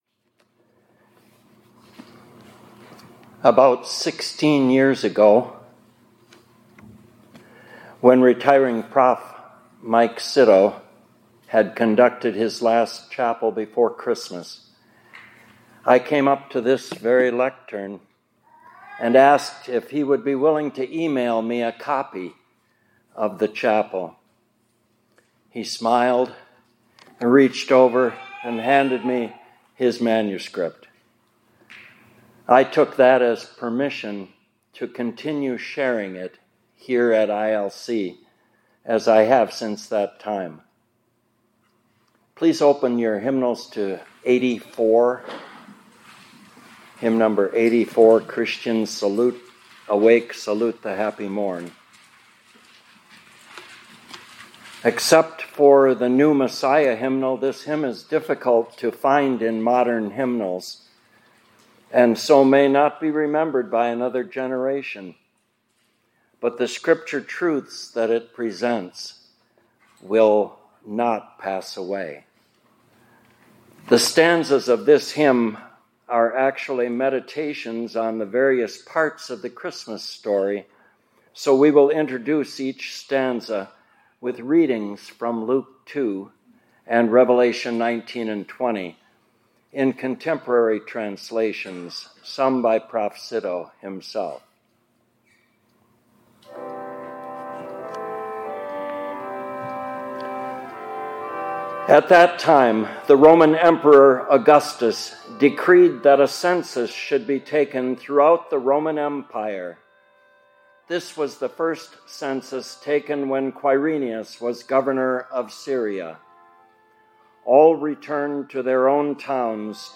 2024-12-11 ILC Chapel — The Christmas Story